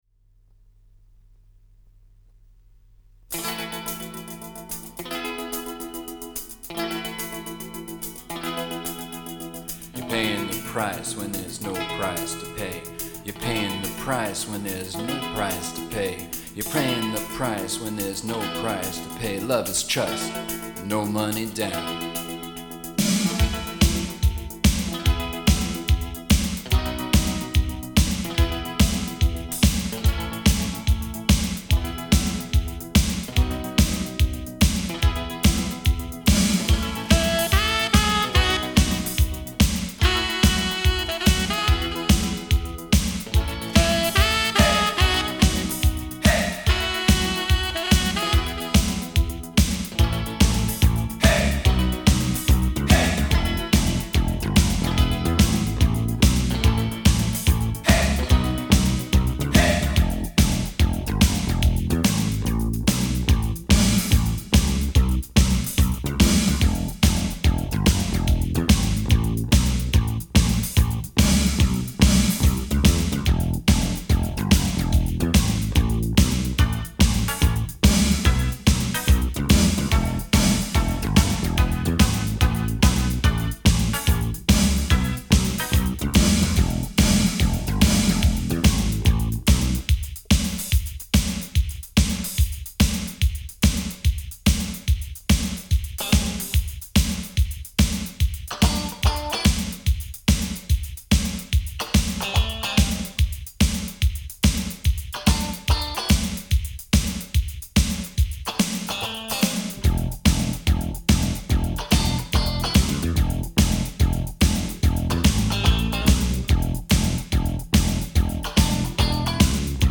the b-side being the “dub” version of the song